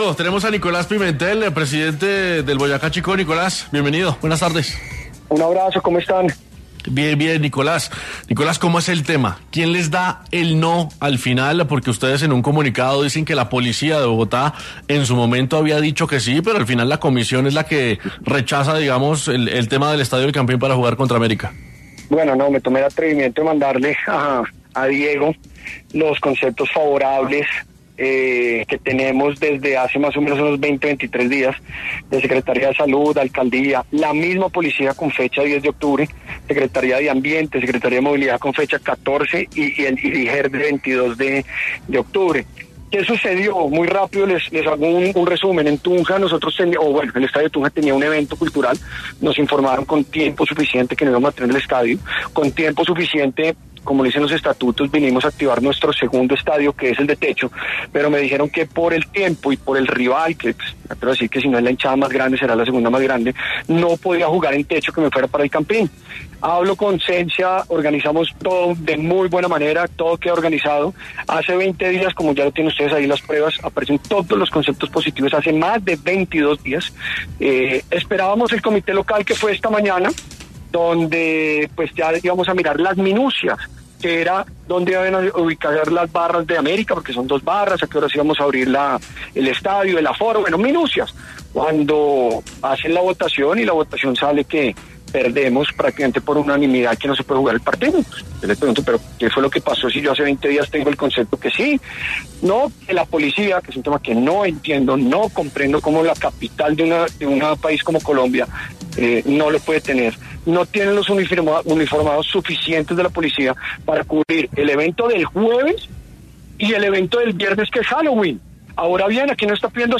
habló con El VBAR Caracol para dar claridad sobre lo ocurrido